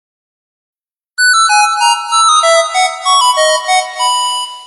分类: 短信铃声